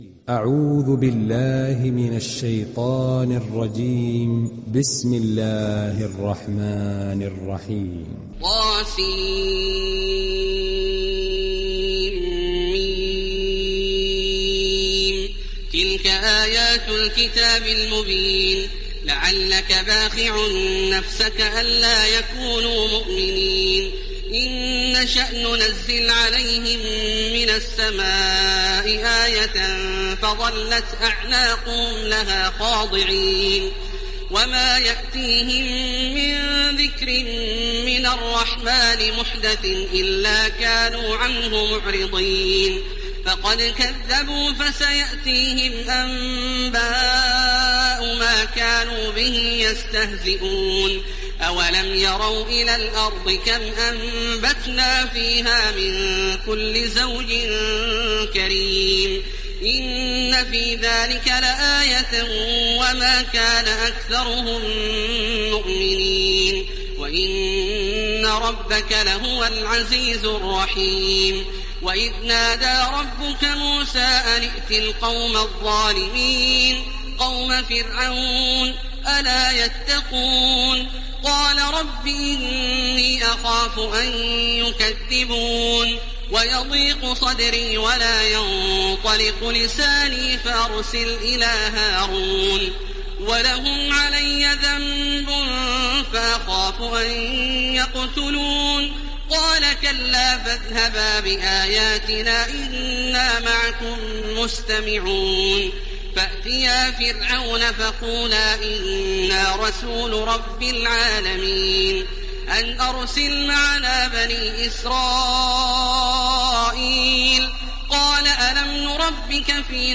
Sourate Ash Shuara Télécharger mp3 Taraweeh Makkah 1430 Riwayat Hafs an Assim, Téléchargez le Coran et écoutez les liens directs complets mp3
Télécharger Sourate Ash Shuara Taraweeh Makkah 1430